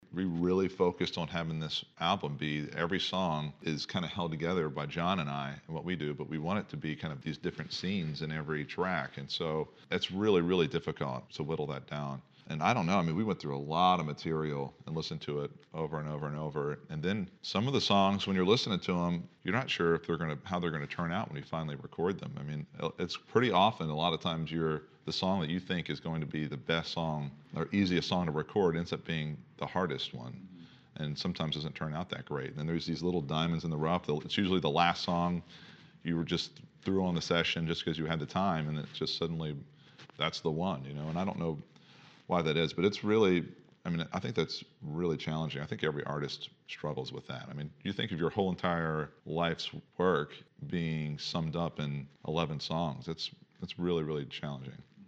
BROTHERS OSBORNE’S TJ OSBORNE SAID IT WAS A BIT OF A CHALLENGE PICKING SONGS FOR THEIR DEBUT ALBUM.
CUT-XX-Brothers-Osborne-picking-songs-for-album.mp3